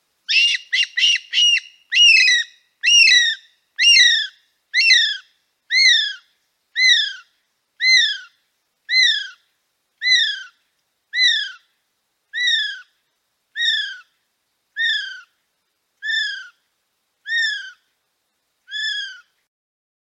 Звуки птицы киви
На этой странице собраны звуки птицы киви — уникальной нелетающей птицы из Новой Зеландии. Вы можете слушать онлайн или скачать её голоса в формате mp3: от нежных щебетаний до характерных криков.